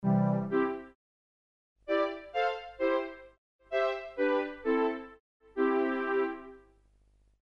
描述：4条弦乐循环。两把小提琴和一把大提琴
Tag: 130 bpm Orchestral Loops Strings Loops 1.24 MB wav Key : Unknown